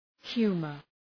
Προφορά
{‘hju:mər}